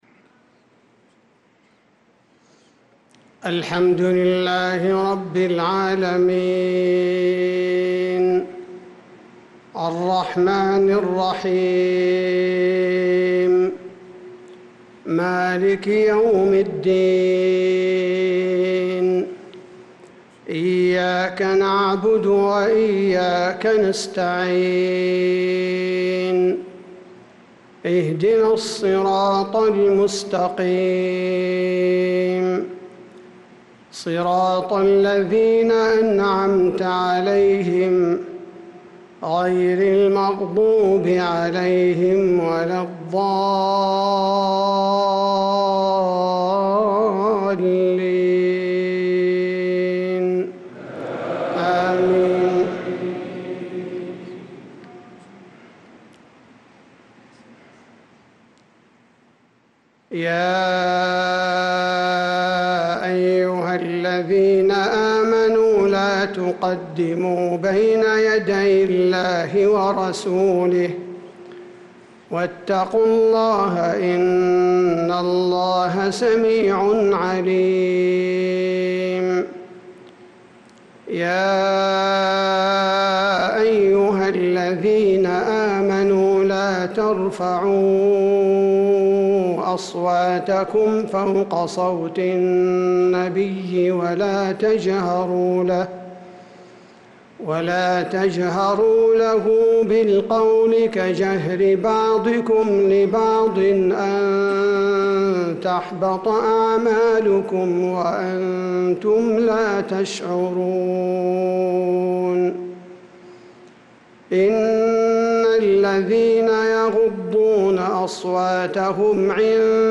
صلاة العشاء للقارئ عبدالباري الثبيتي 7 ذو الحجة 1445 هـ